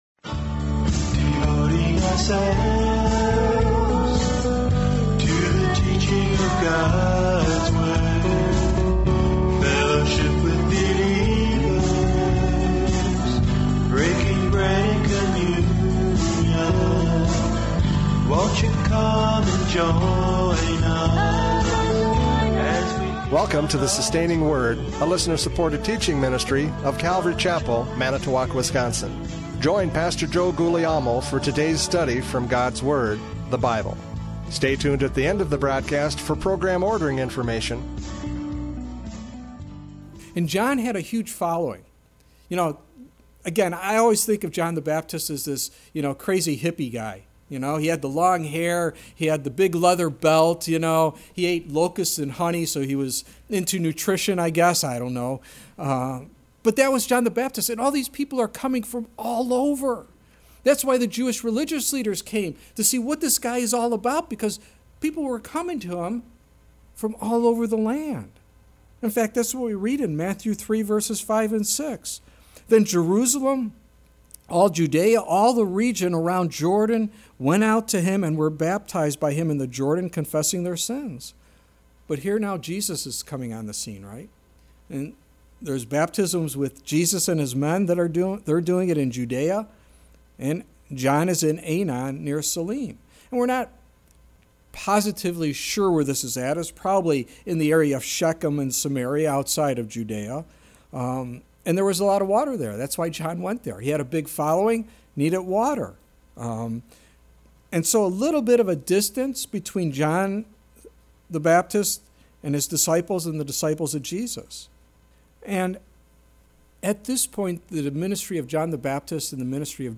John 3:22-36 Service Type: Radio Programs « John 3:22-36 Absolute Surrender!